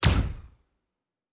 pgs/Assets/Audio/Sci-Fi Sounds/Weapons/Weapon 01 Shoot 1.wav at master
Weapon 01 Shoot 1.wav